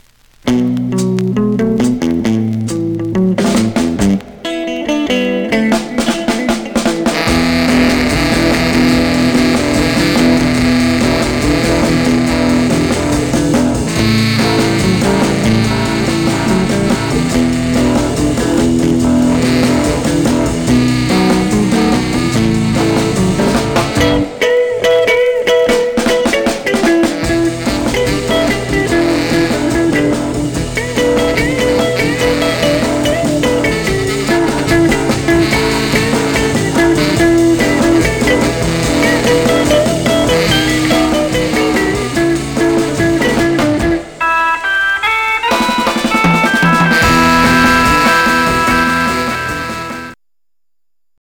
Stereo/mono Mono
R & R Instrumental